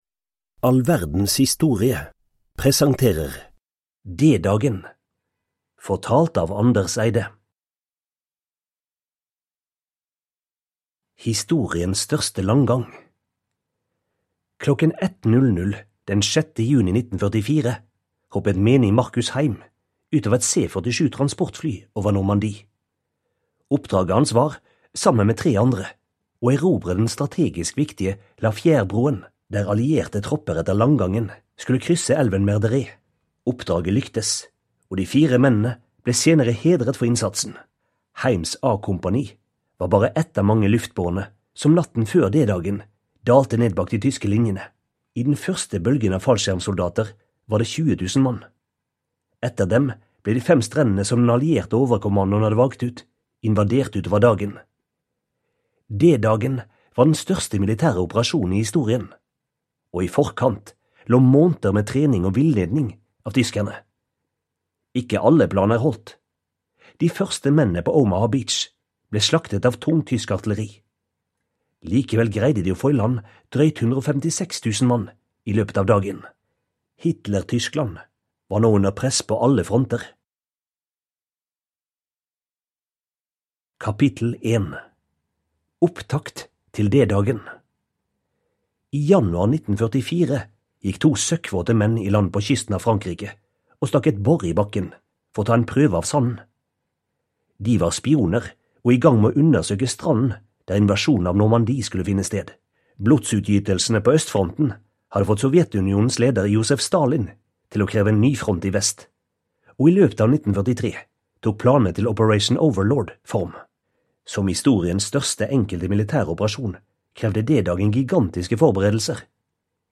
D-dagen (ljudbok) av All verdens historie